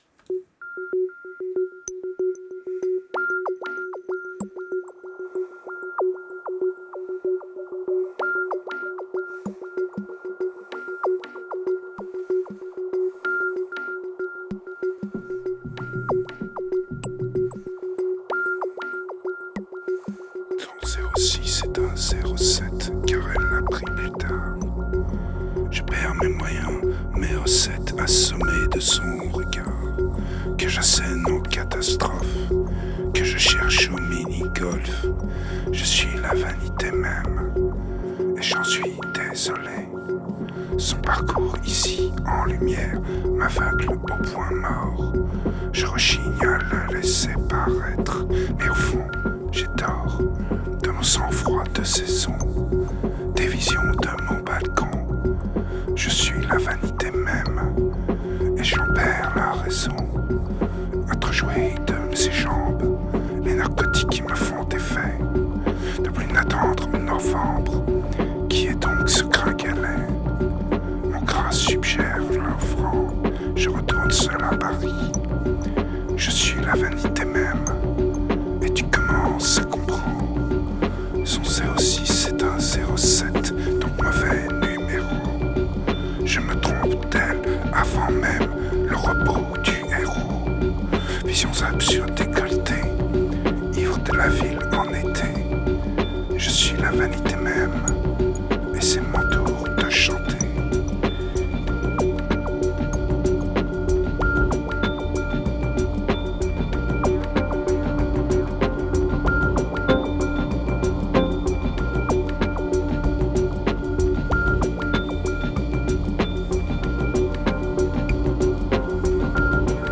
3242📈 - 87%🤔 - 95BPM🔊 - 2015-08-15📅 - 1233🌟